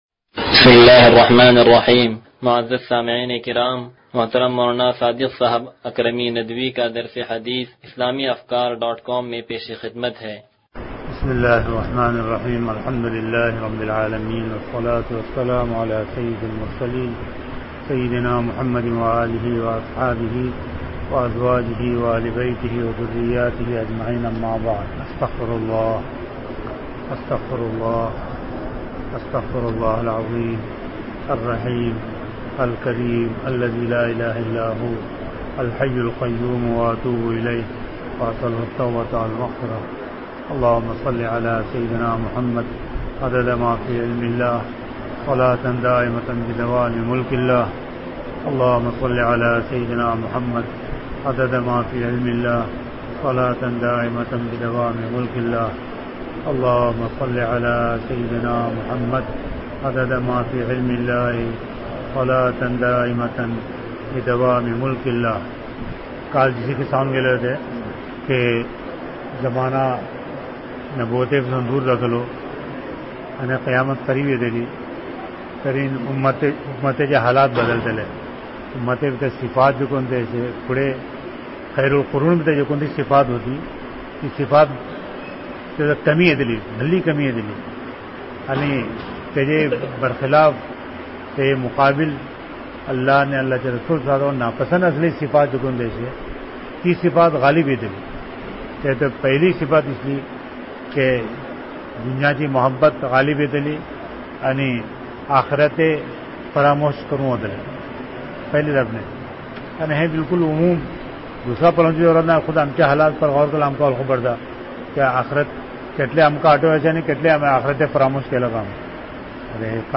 درس حدیث نمبر 0136